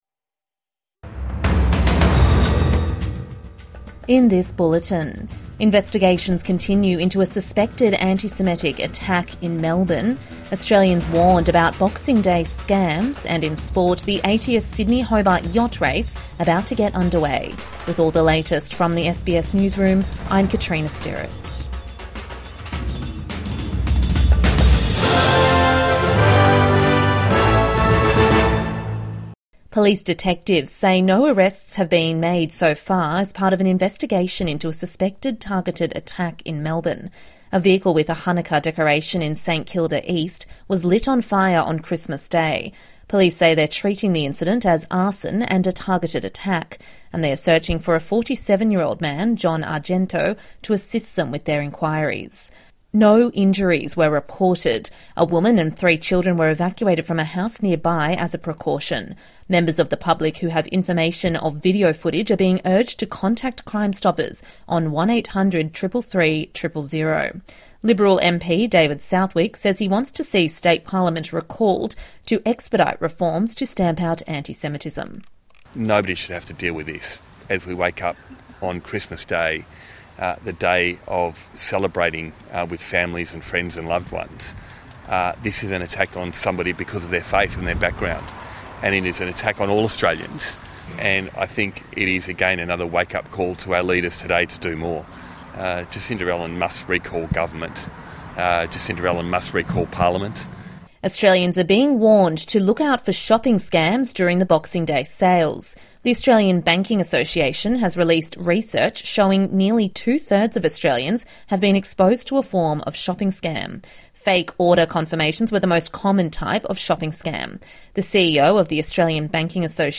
Investigations into suspected antisemitic attack in Melbourne | Midday News Bulletin 26 December 2025